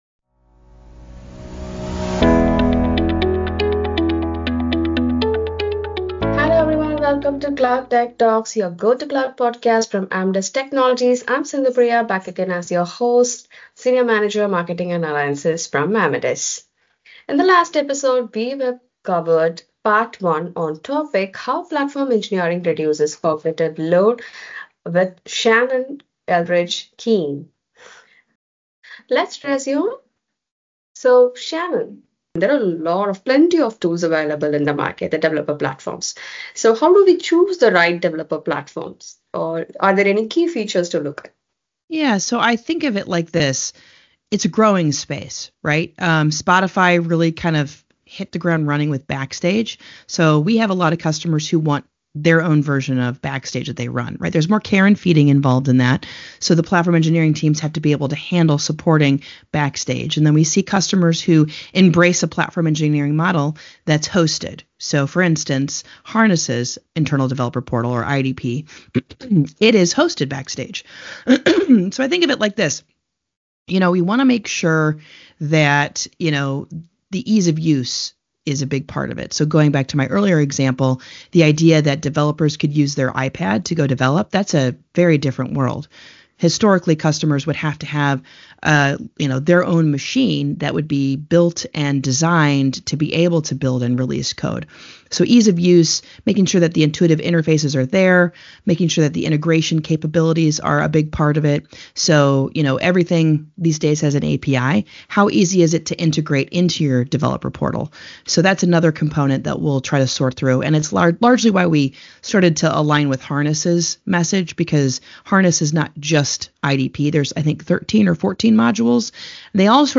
Join us as we delve into the latest developments and trends in cloud including topics such as cloud FinOps, migration strategies, and more. Each episode features expert guests from the tech industry, who share their insights, experiences, and practical tips to help you navigate the complexities of the cloud.